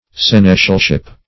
Search Result for " seneschalship" : The Collaborative International Dictionary of English v.0.48: Seneschalship \Sen"es*chal*ship\, n. The office, dignity, or jurisdiction of a seneschal.
seneschalship.mp3